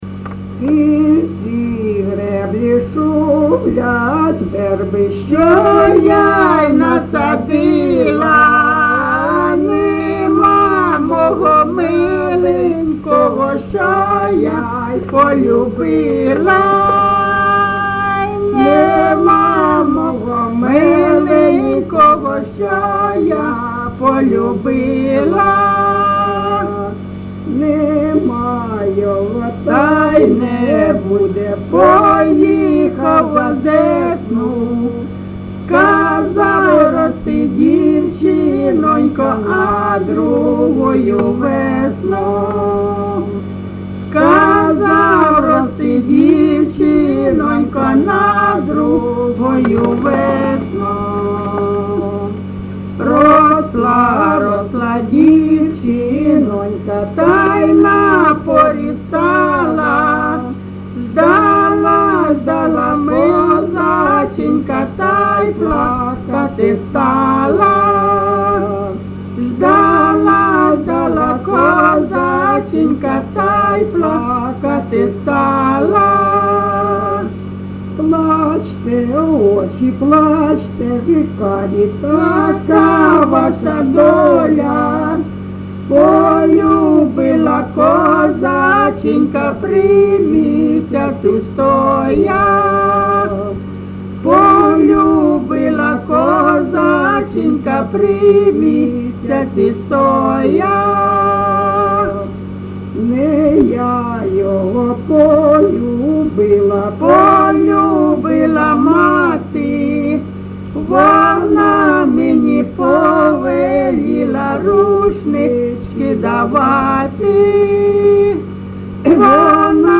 ЖанрПісні з особистого та родинного життя